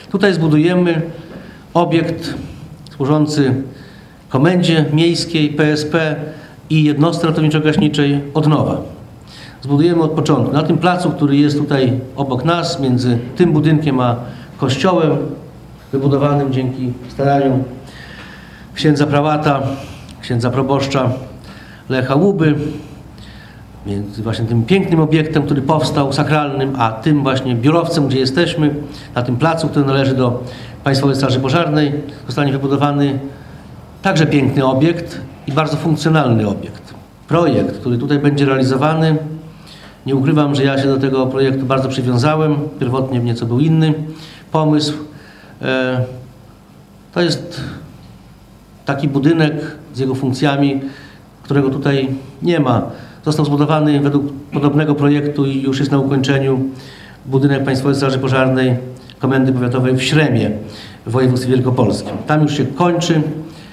Informację na ten temat Jarosław Zieliński przedstawił podczas spotkania z suwalskimi strażakami w świetlicy jednostki przy ulicy Pułaskiego.